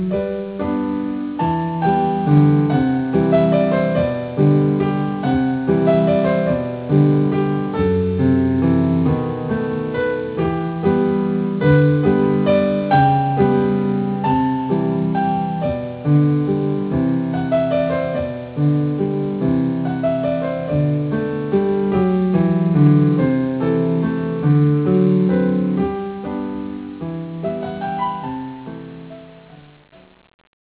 おなじみの曲がおしゃれにかつ弾き易く編曲されています。